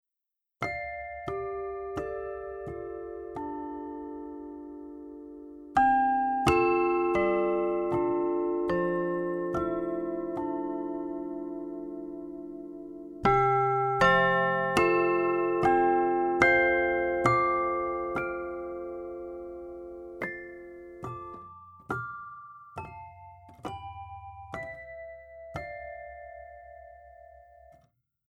Es hat einen fragilen Klang, bei dem sich hölzerne und metallische Klangfarben mischen.
Als einziges Rhodes-Piano verfügt es über einen eingebauten Röhren-Verstärker und Lautsprecher.